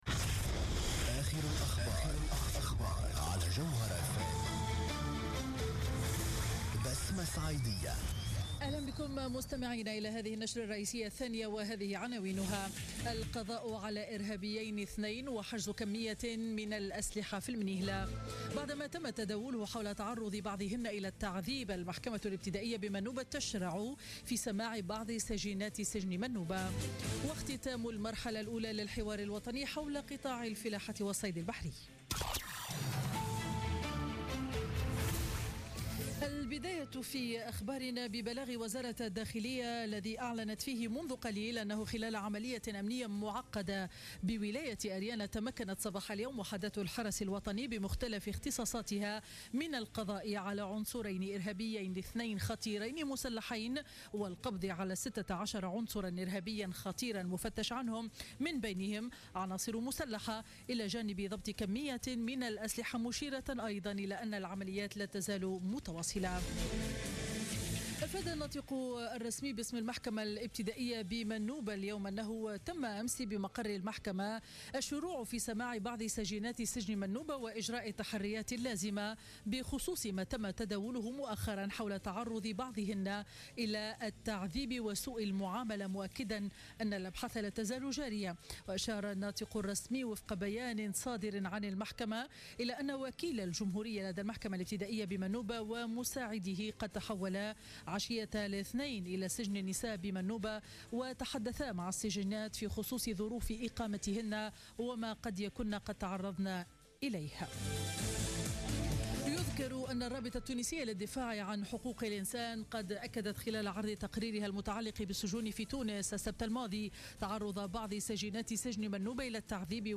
نشرة أخبار منتصف النهار ليوم الأربعاء 11 ماي 2016